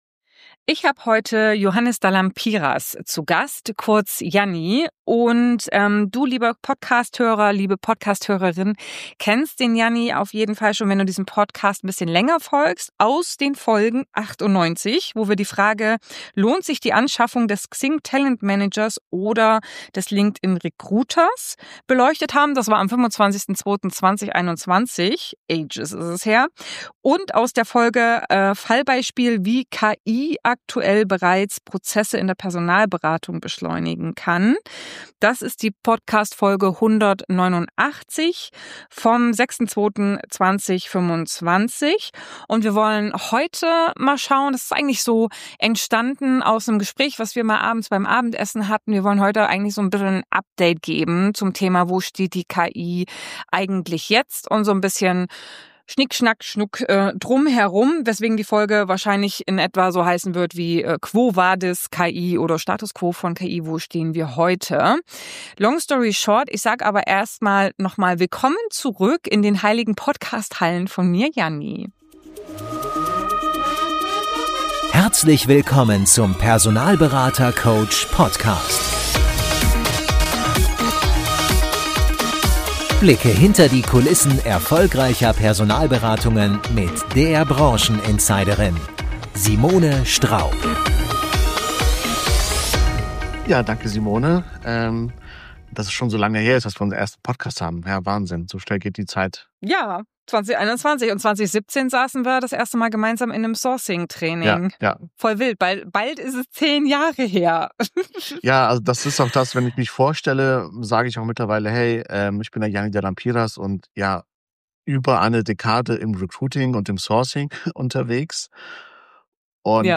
KI im Recruiting - wo stehen wir? - Interview